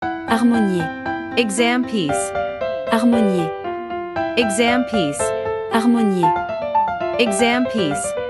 • 人声数拍